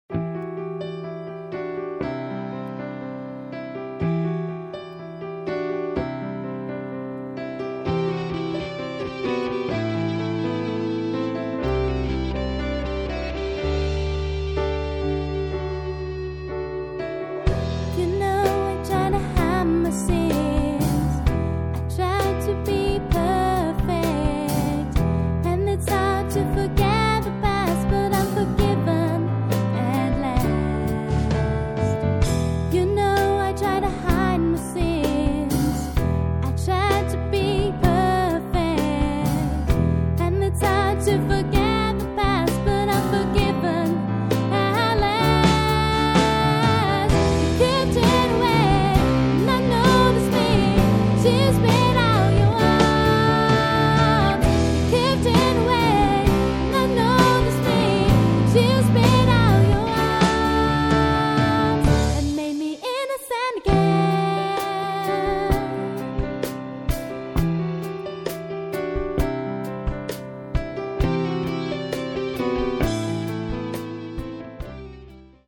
Rock-Eigenkompositionen